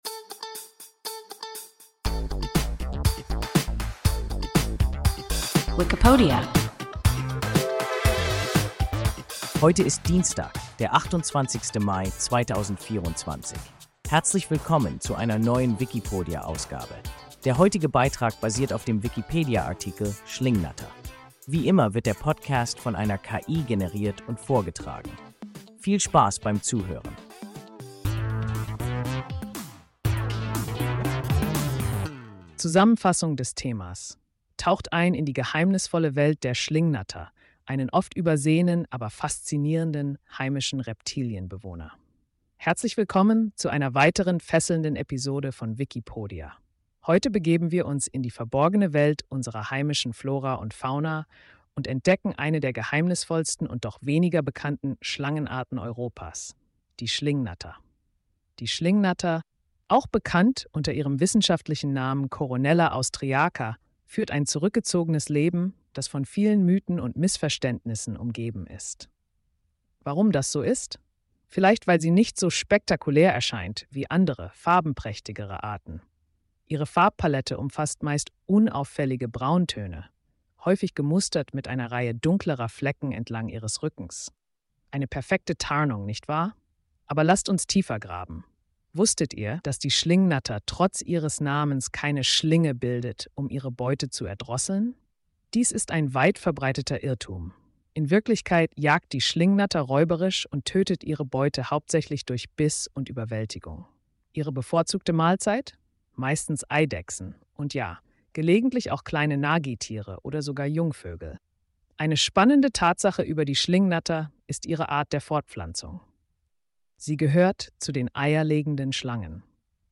Schlingnatter – WIKIPODIA – ein KI Podcast